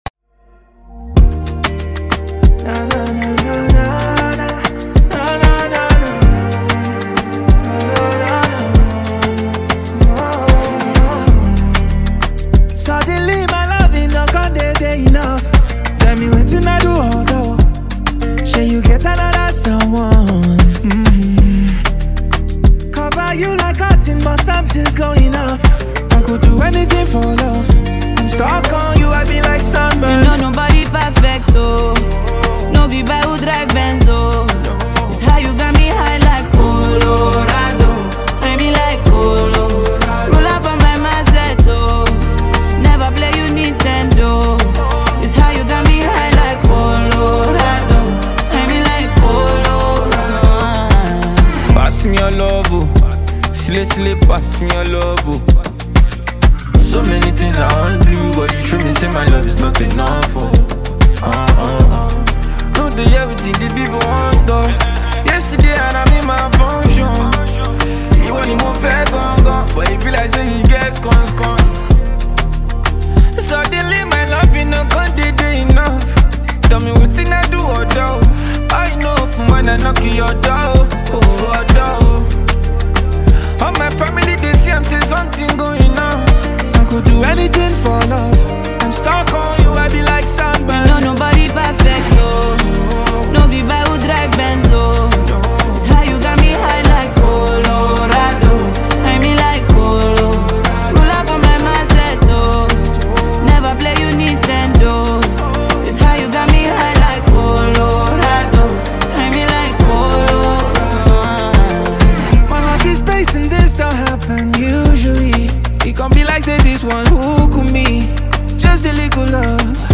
smooth delivery